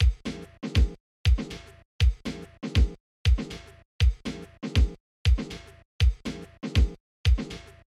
工业氛围
描述：尘土飞扬的笨重环境鼓声
Tag: 120 bpm Ambient Loops Drum Loops 1.35 MB wav Key : Unknown